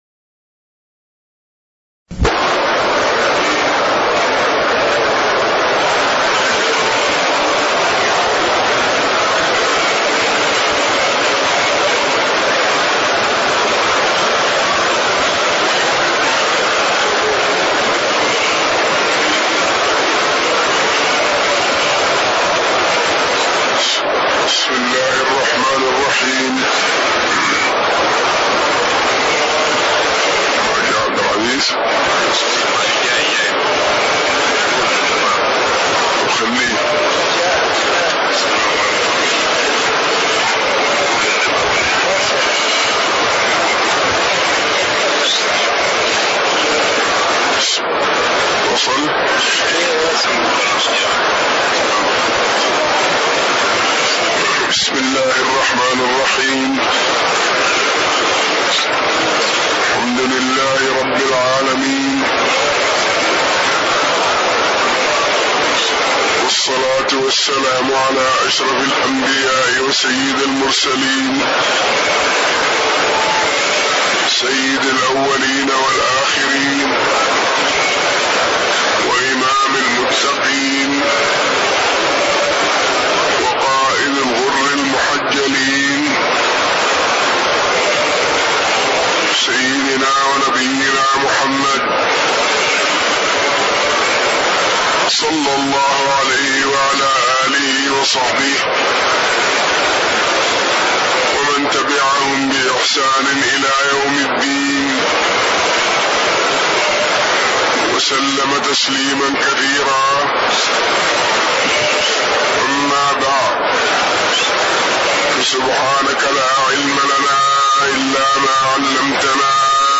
تاريخ النشر ٢٩ ذو الحجة ١٤٣٦ هـ المكان: المسجد النبوي الشيخ